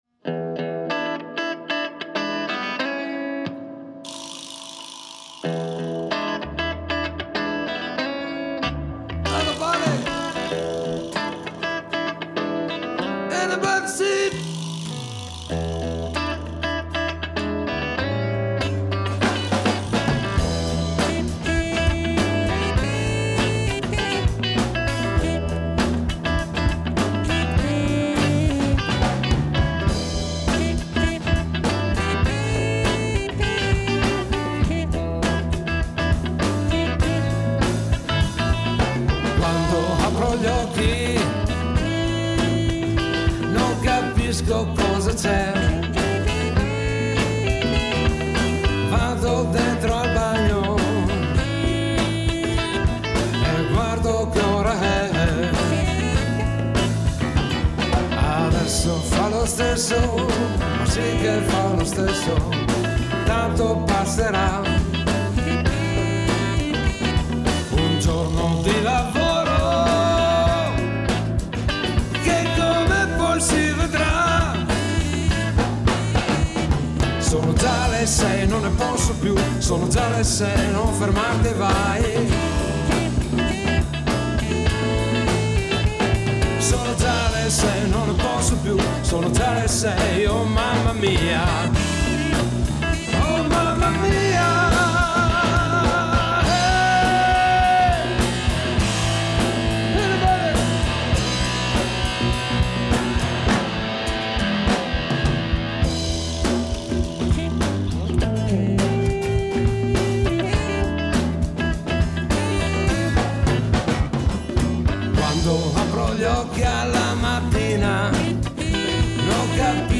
GenereBlues / Soul